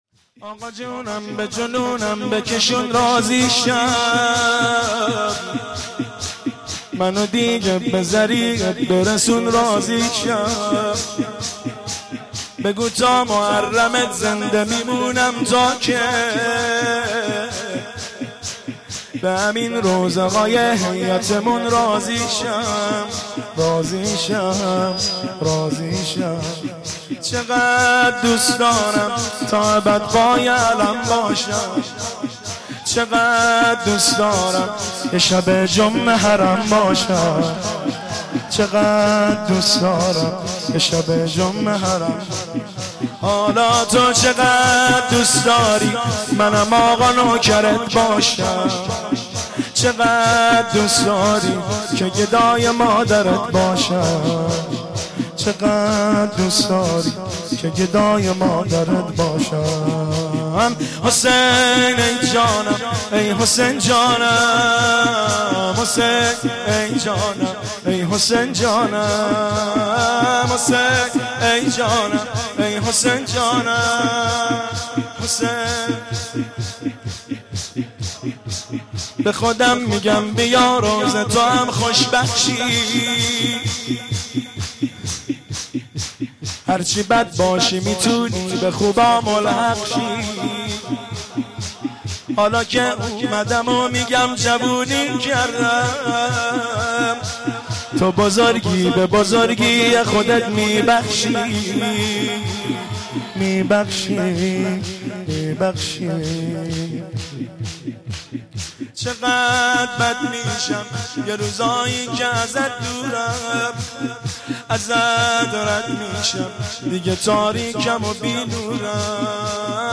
مجموعه نوحه های جلسه هفتگی
که در بین الحرمین تهران اجرا شده است
منی که دل به عشقه تو اسیره ( واحد )
فقط میشه گفت حیف این جلسات که صوتش به این شکله !!